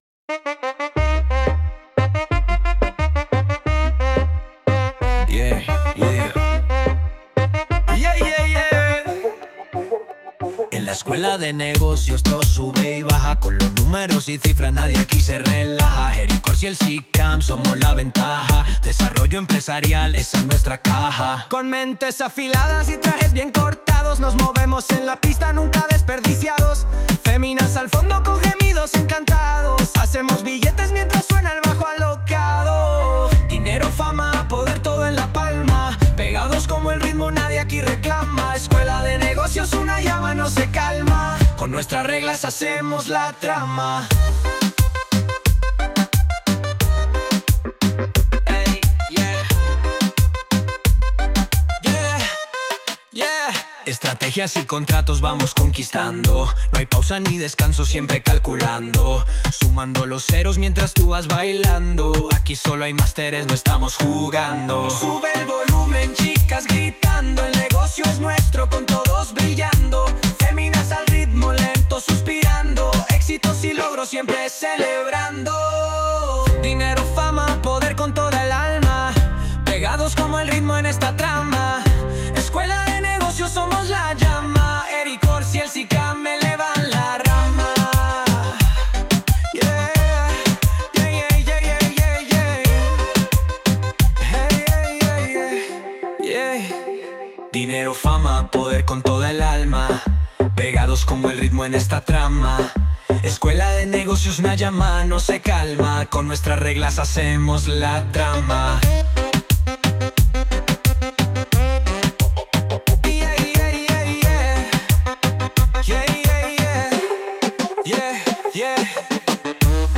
Música del Bar